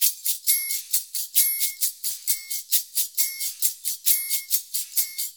Index of /90_sSampleCDs/USB Soundscan vol.56 - Modern Percussion Loops [AKAI] 1CD/Partition A/04-FREEST089